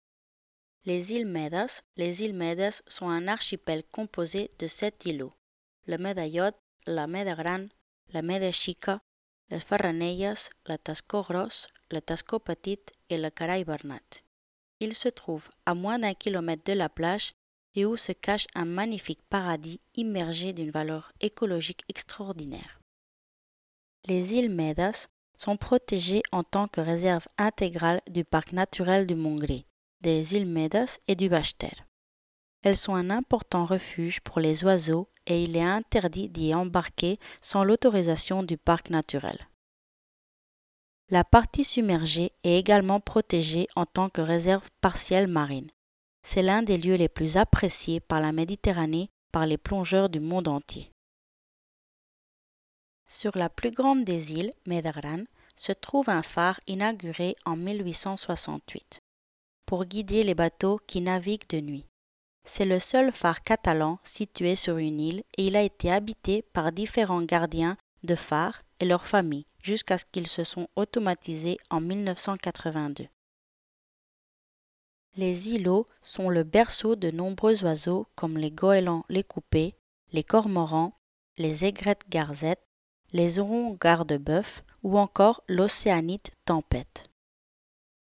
Guide audio